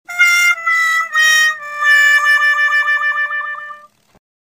Скачать звук грустного тромбона
Разные вариации звучания грустного тромбона (sad trombone) из мема в mp3 для монтажа
10. И мультяшный такой
sad-trombone-16.mp3